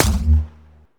hit1.wav